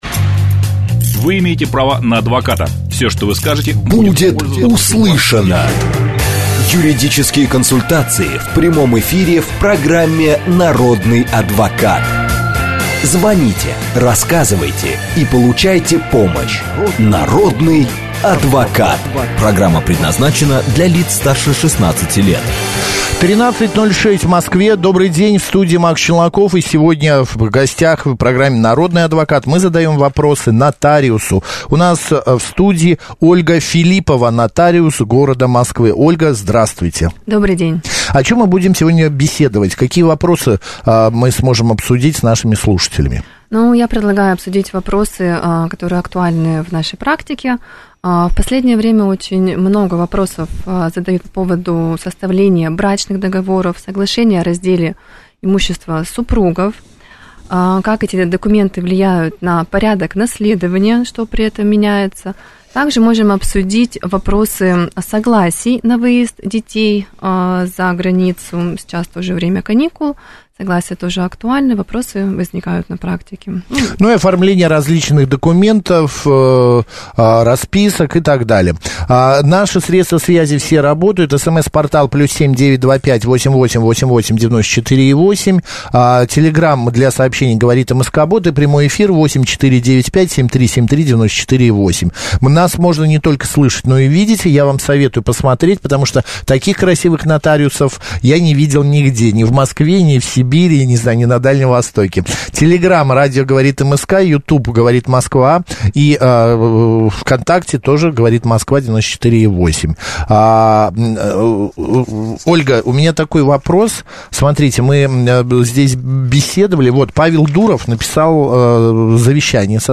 Нотариус в радиоэфире: закрытые завещания из западных фильмов в России — редкость